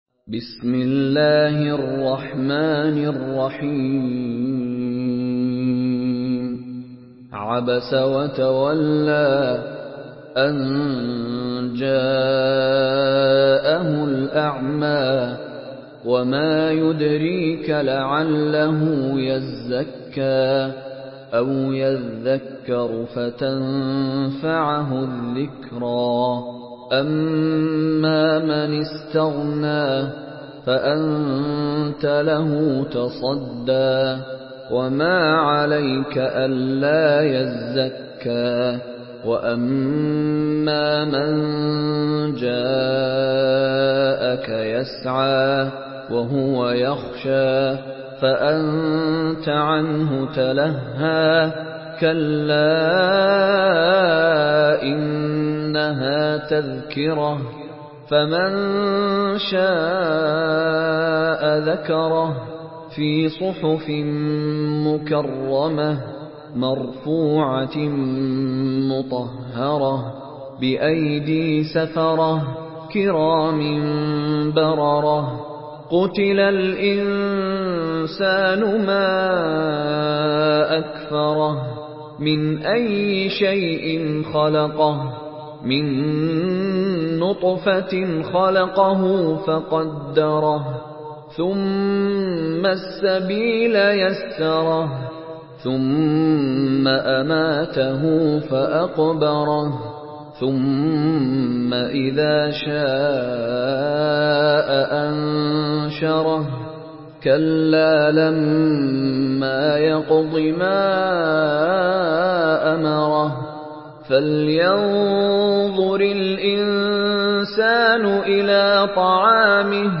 Surah ‘আবাসা MP3 by Mishary Rashid Alafasy in Hafs An Asim narration.
Murattal Hafs An Asim